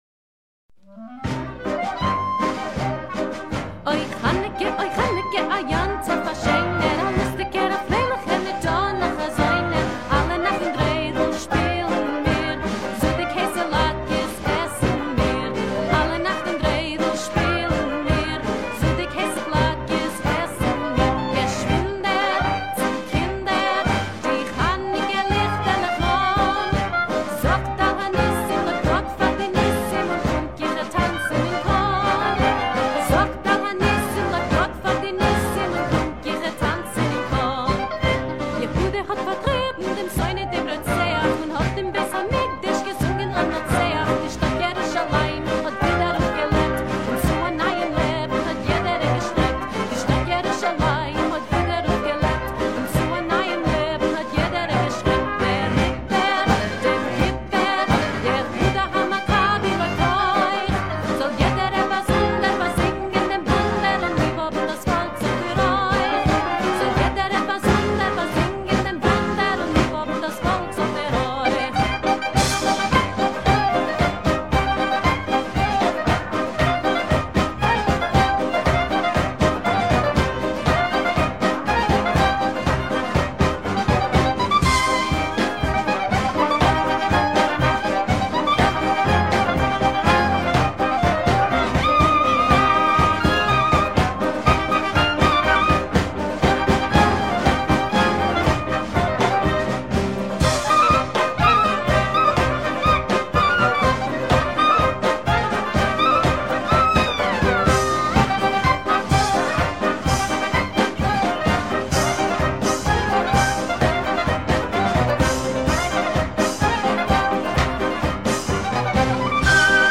die meist zu mitreißenden Rhythmen vorgetragen werden